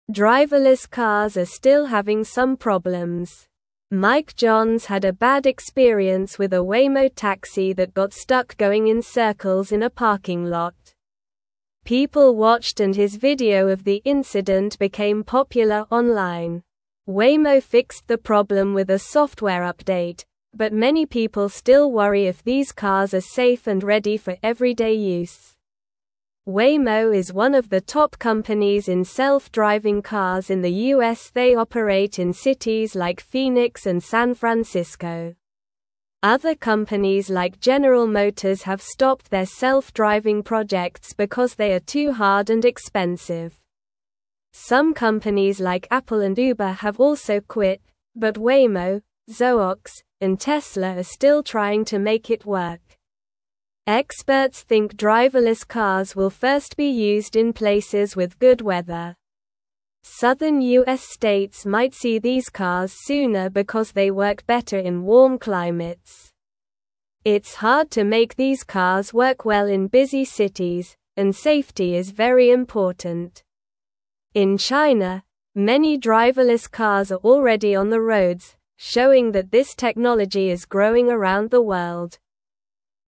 Slow
English-Newsroom-Lower-Intermediate-SLOW-Reading-Driverless-Cars-Safe-or-Not-for-Everyone.mp3